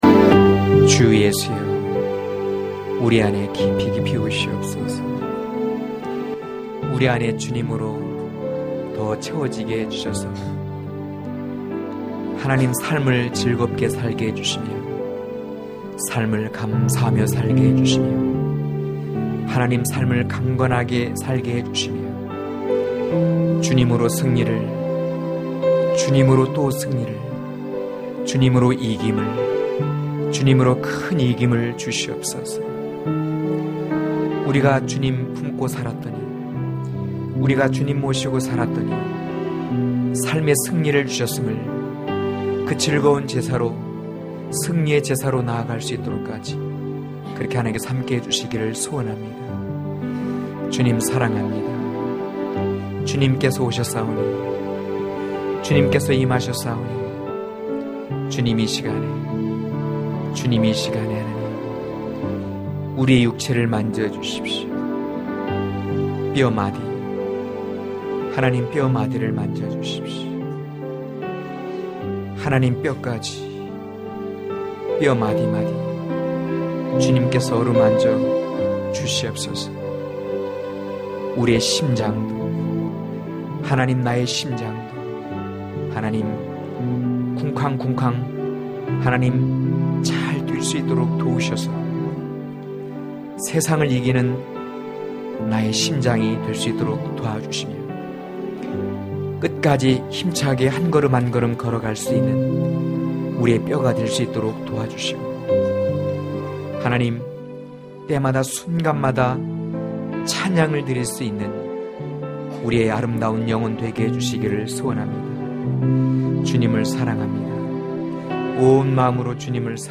강해설교 - 18.신랑의 집에서 다시 시작하자(아8장1-7절)